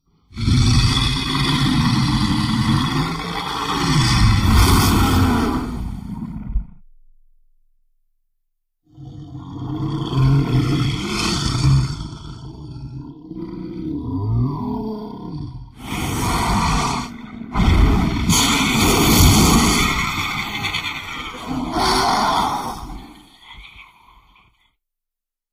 Godzilla: Final Wars - Monster-X Roar-sound-HIingtone
godzilla-final-wars-monster-x-roar_25524.mp3